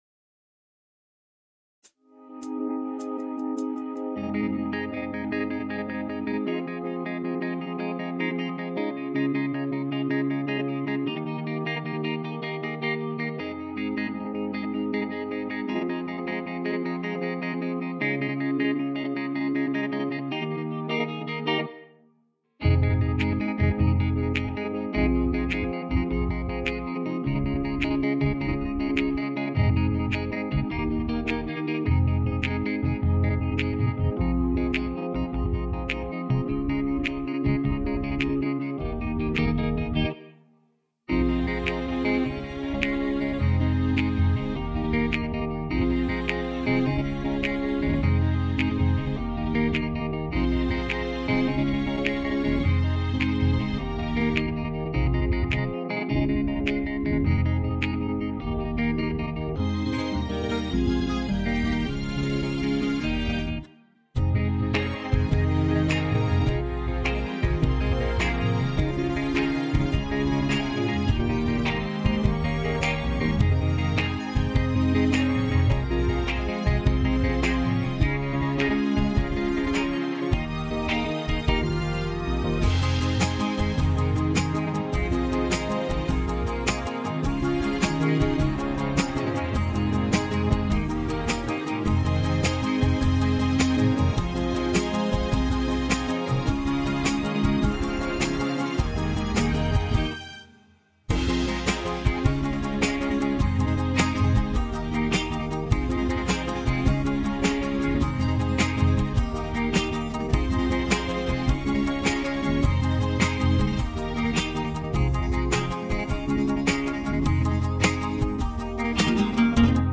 karaoke songs